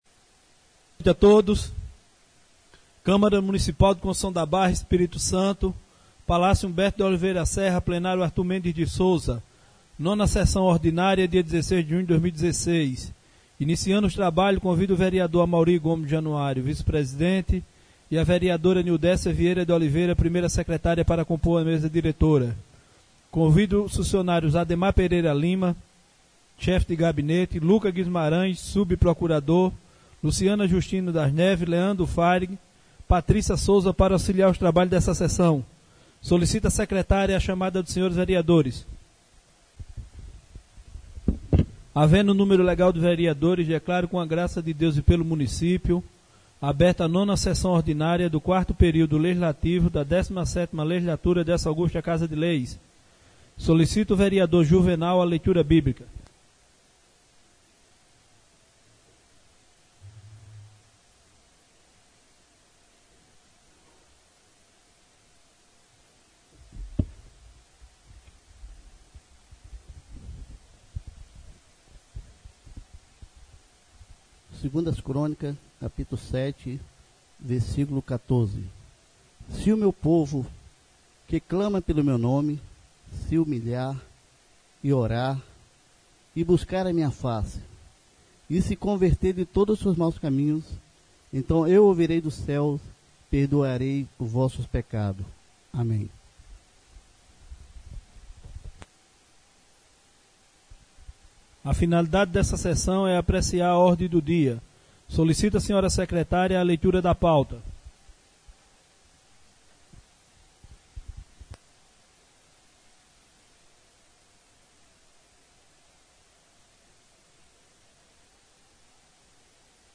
9ª SESSÃO ORDINÁRIA EM 16 DE JUNHO DE 2016 SEDE